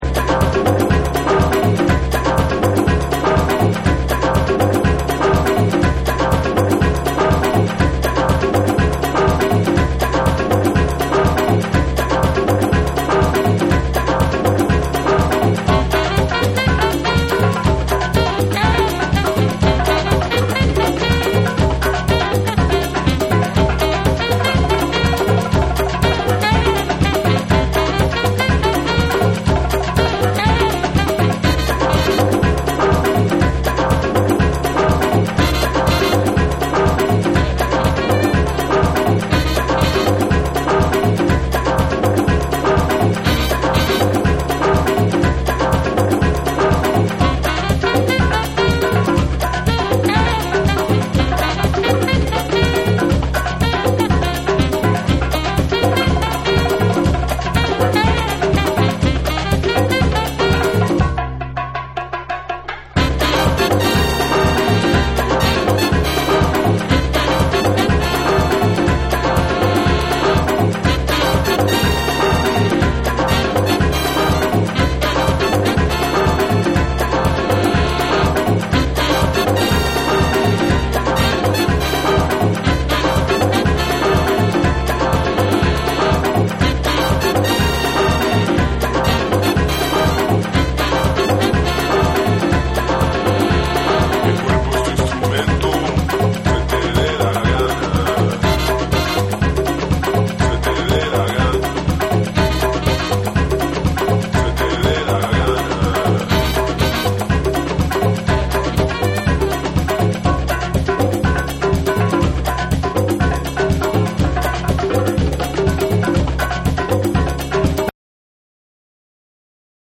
日本のクラブジャズ・ユニット
JAPANESE / BREAKBEATS